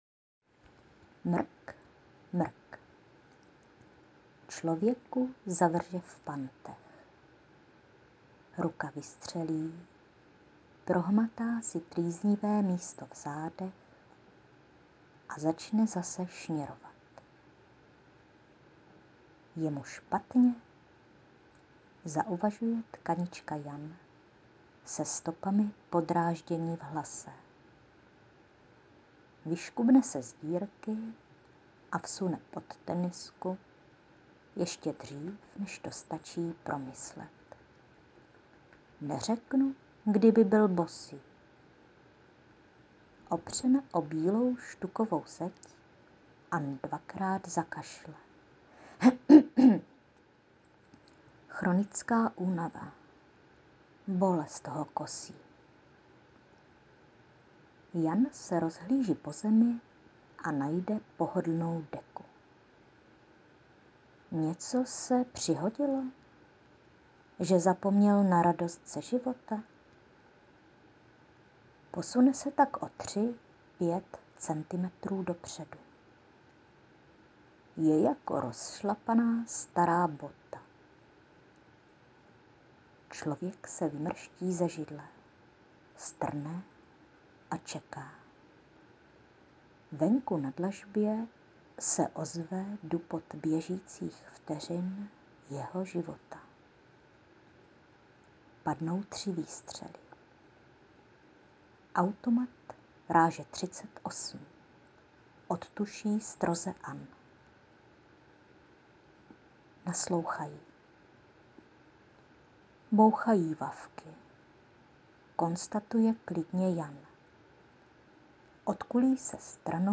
P.S.A bonus je Tvůj laskavostí prodchnutý hlas,z kterého čiší zvláštní Klid...